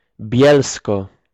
Bielsko [ˈbjɛlskɔ]